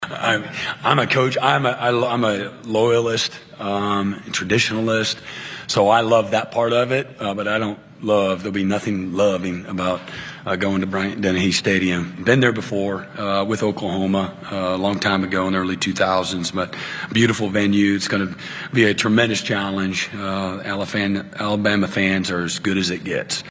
OU head coach Brent Venables broke down the Sooner’s schedule during SEC media days last week.